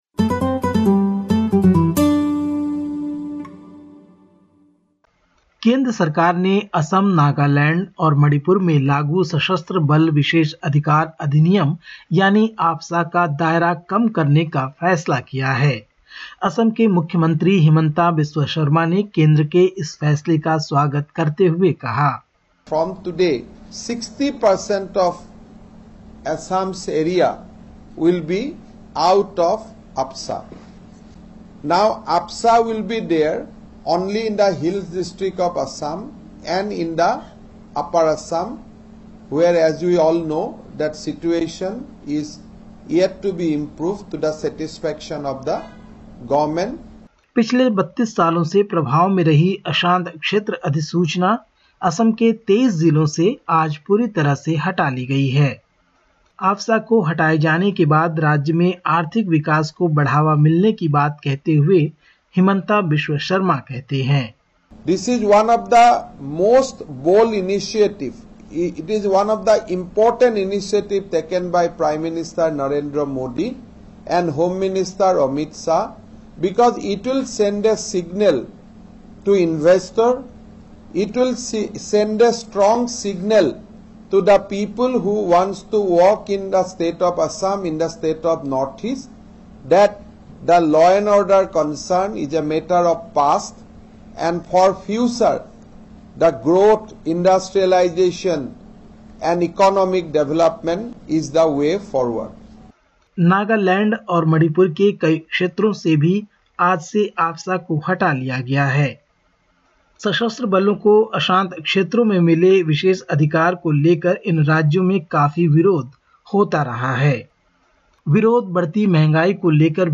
Listen to the latest SBS Hindi report from India. 01/04/2022